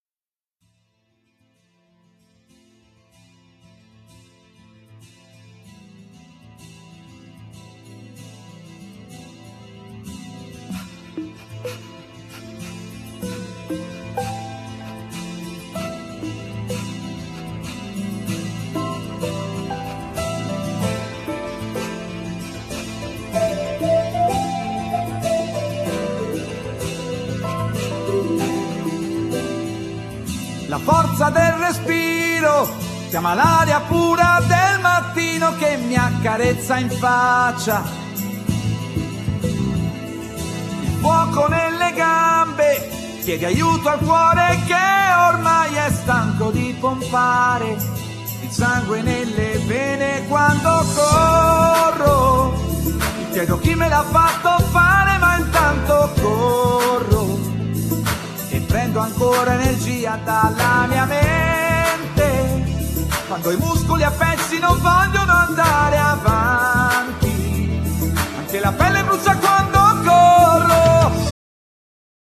Genere : Pop /Jazz